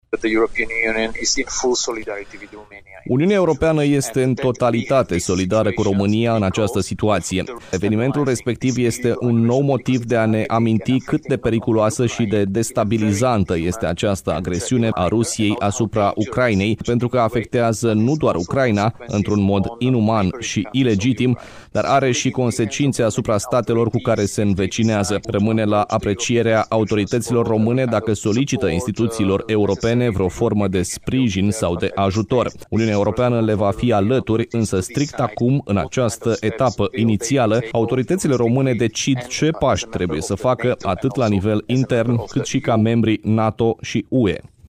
Într-o declarație acordată colegului nostru